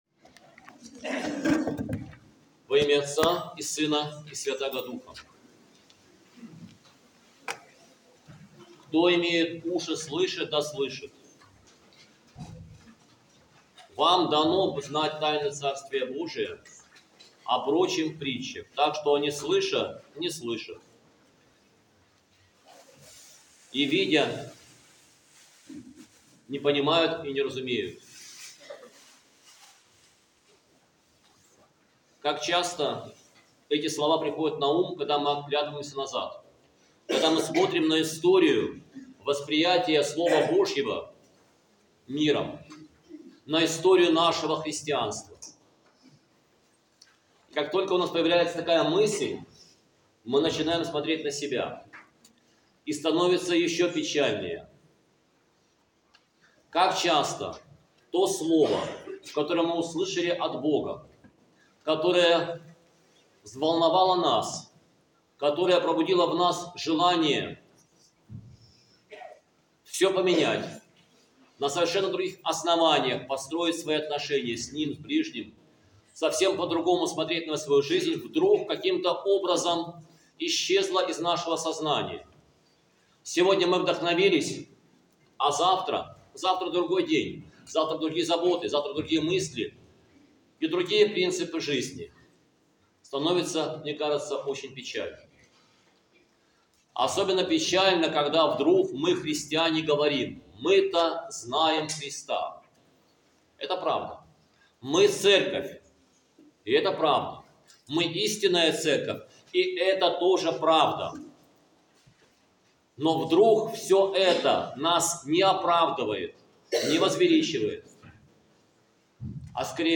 Проповедь с Божественной Литургии 27.10.2024